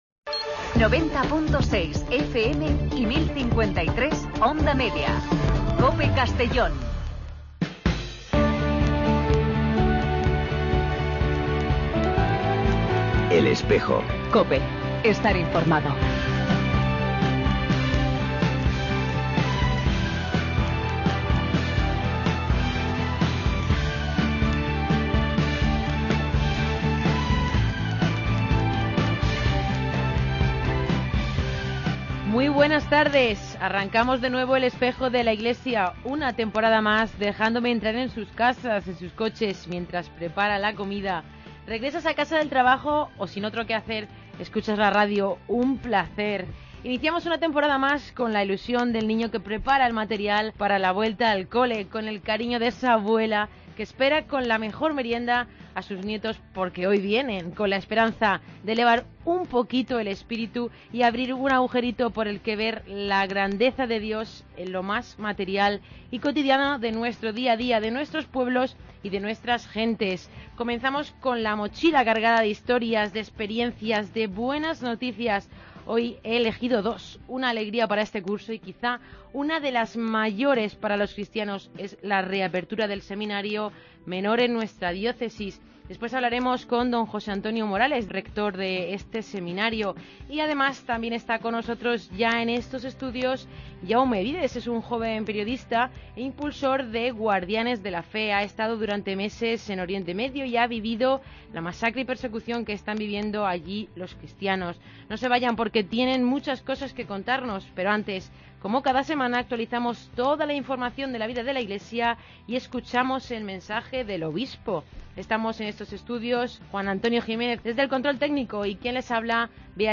AUDIO: El programa de radio de la diócesis de Segorbe-Castellón. Con entrevistas, comentarios, información...